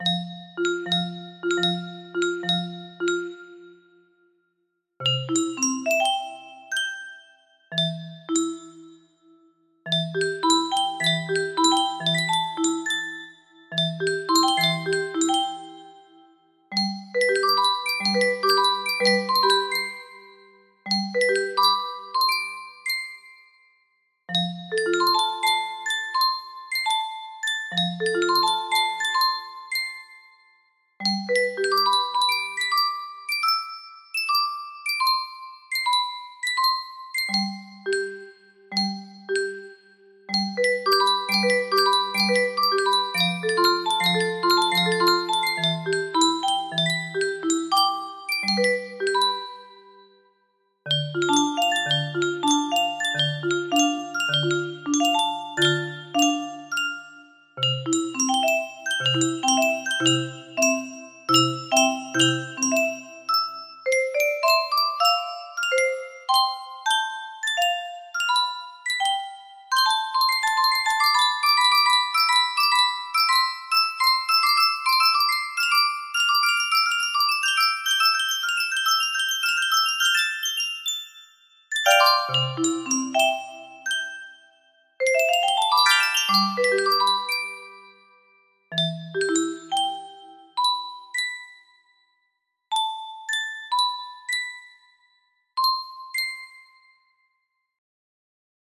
Full range 60
An original music box piece I composed today.